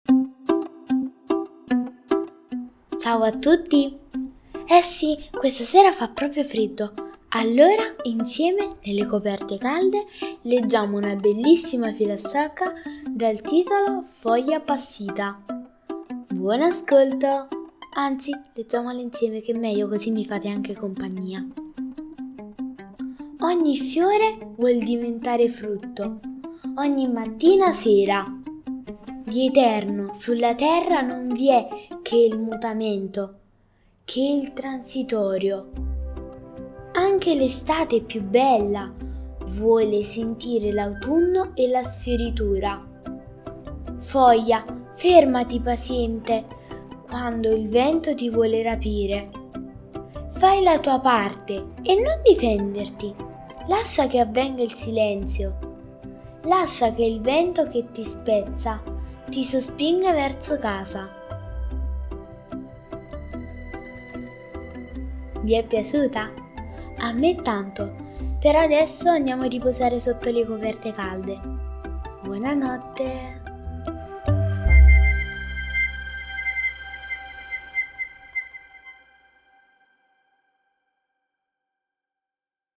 mamma legge la fiaba
Le favole della buonanotte